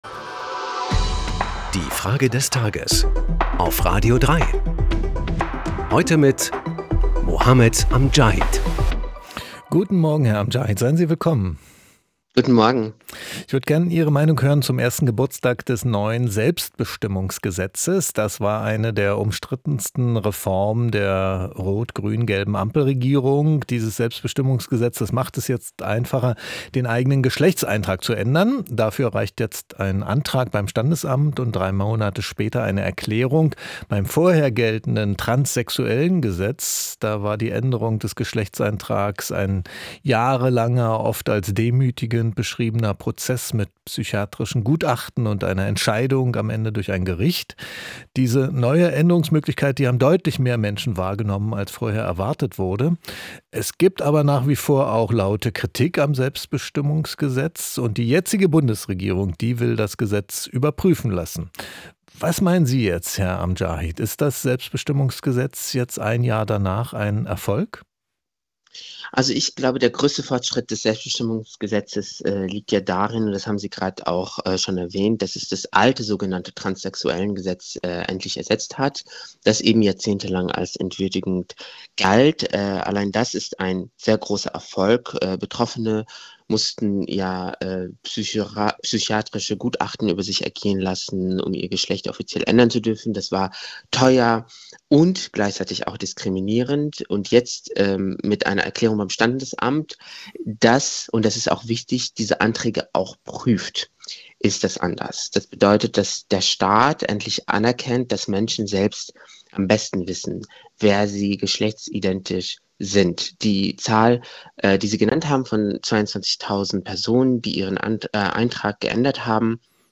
Kommentator, den Journalisten und Publizisten